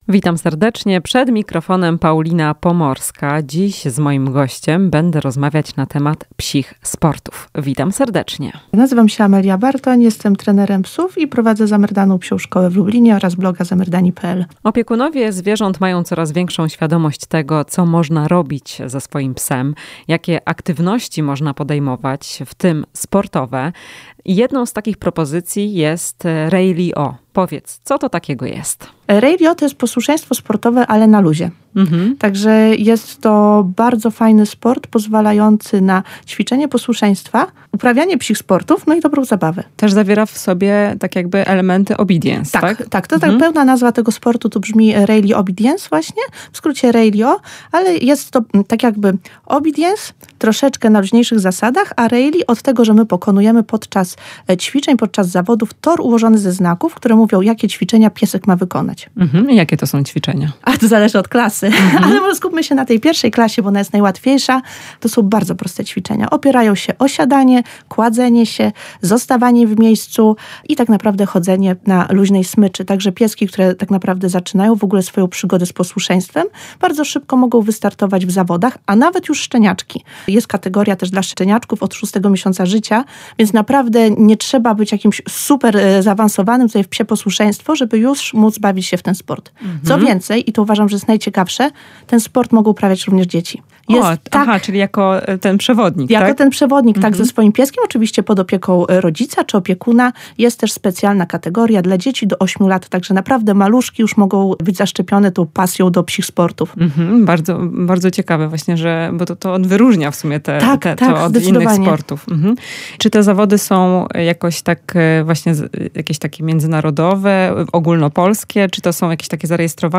W „Chwili dla pupila” porozmawiamy o psich sportach. Czym jest Rally-O – w rozmowie z trenerem psów